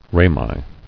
[ra·mi]